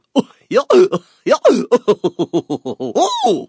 Goofy Laugh Sound Effect Free Download
Goofy Laugh